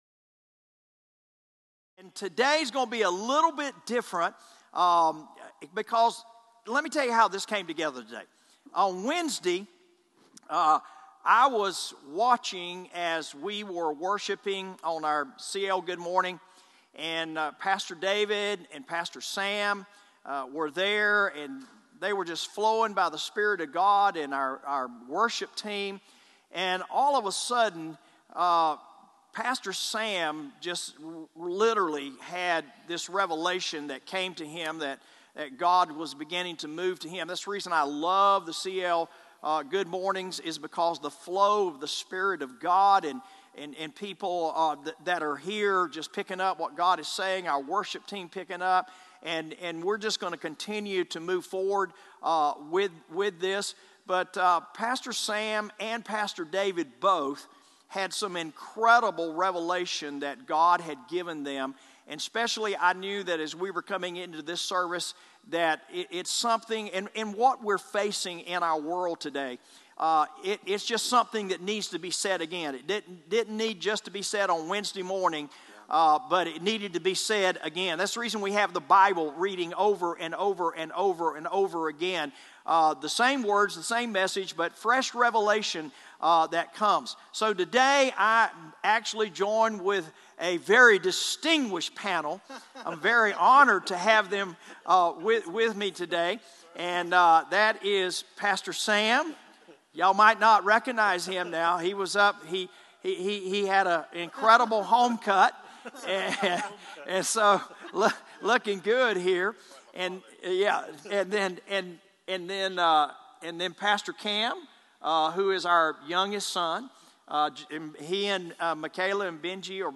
Today's message is an incredible panel